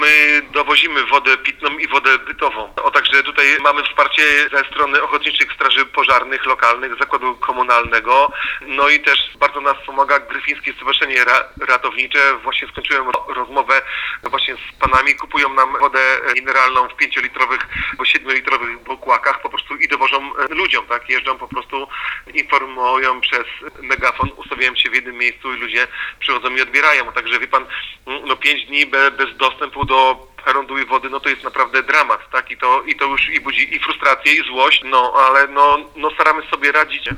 – Mówi Paweł Wróbel, wójt Widuchowej.